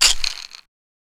Percs
blood money shaker.wav